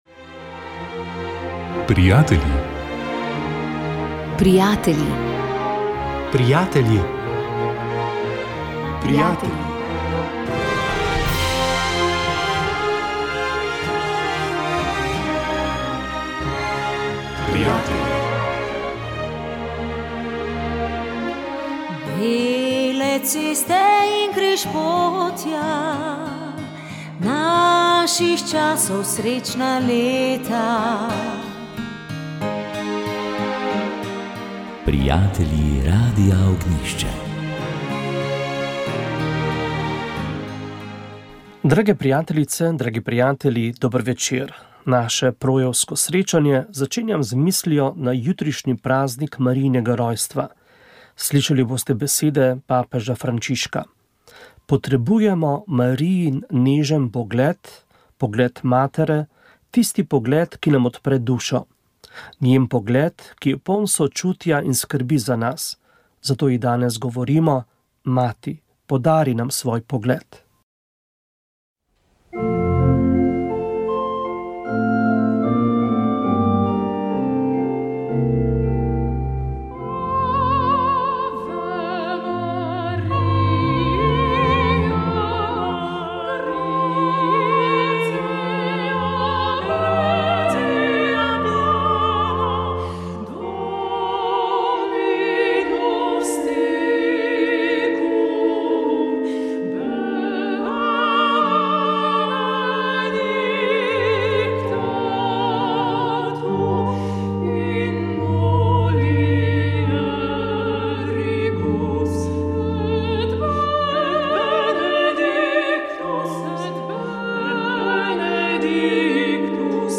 Oddaja PRO je bila neke vrste vigilija pred praznikom Marijinega rojstva. Slišali smo Marijine pesmi in besede papeža Frančiška o Mariji.